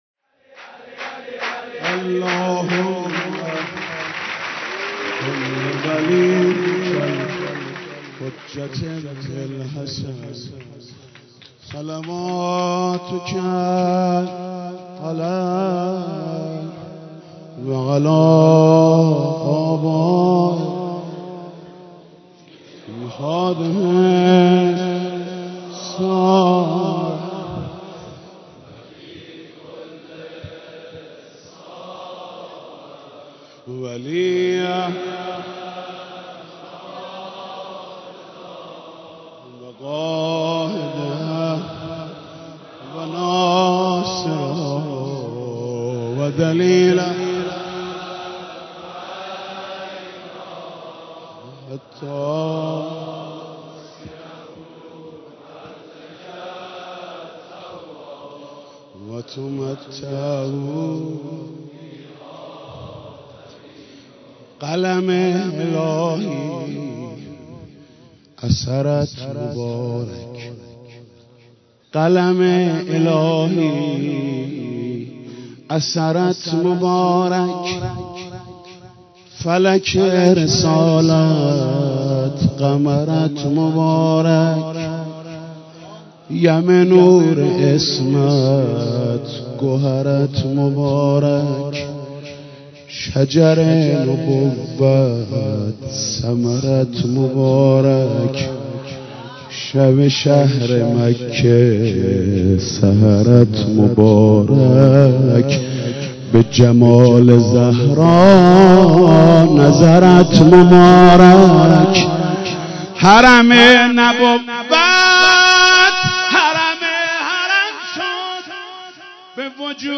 مدیحه و مولودی حضرت فاطمه زهرا (س).mp3